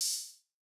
MOO Open Hat.wav